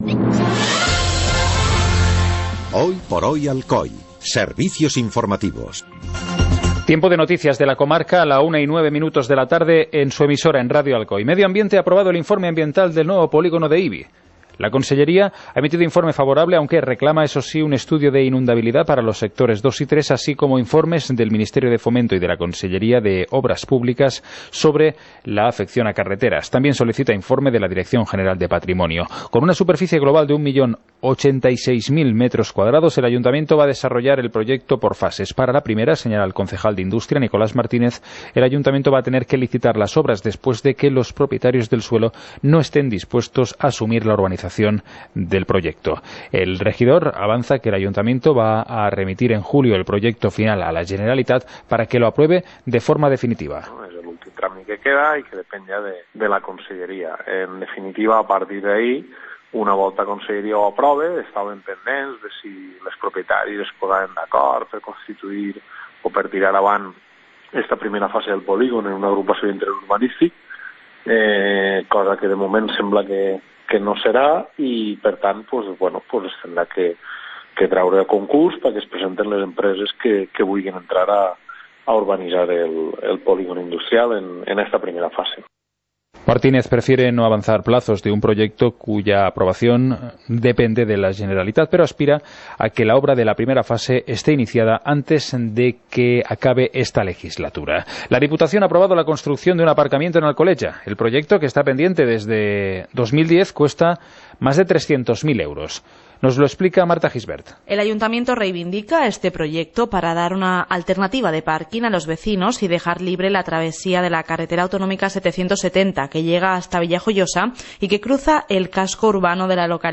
Informativo comarcal - jueves, 29 de junio de 2017